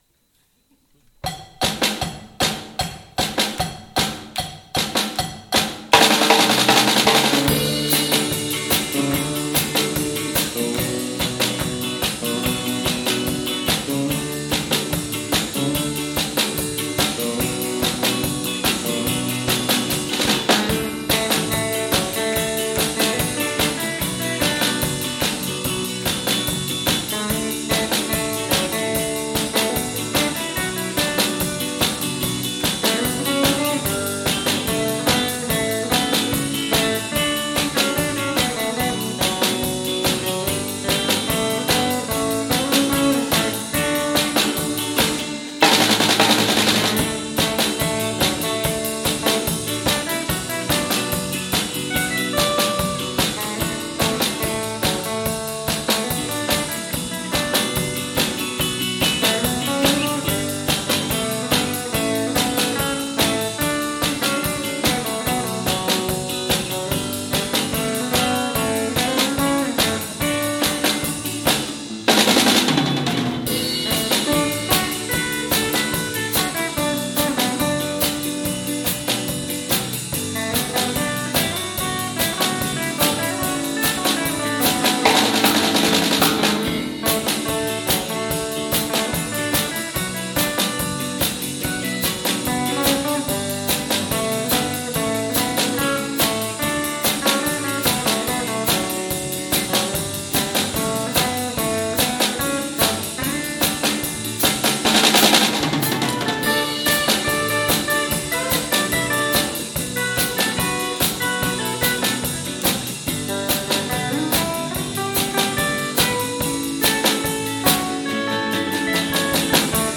桂病院土曜コンサート-3 | The Arou Can